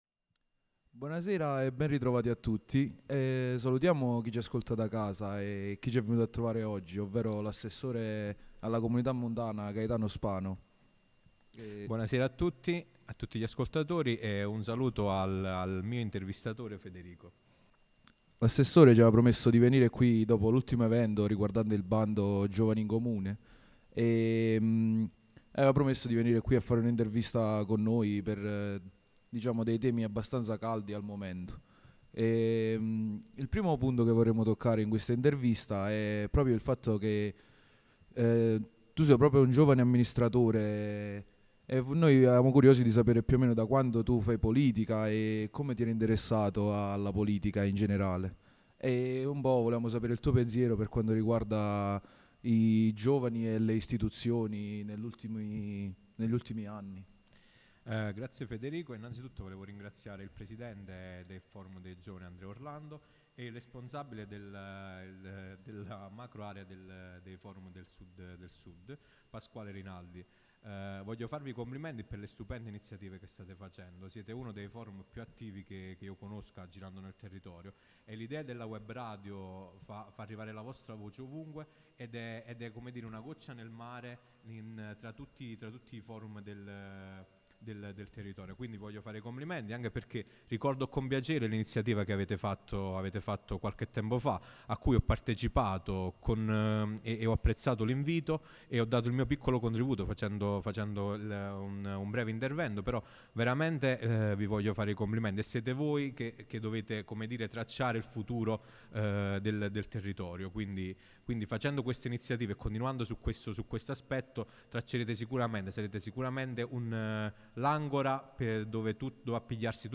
Podcast 07 – Intervista Assessore Gaetano Spano Comunità Montana Vallo Diano